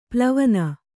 ♪ plavana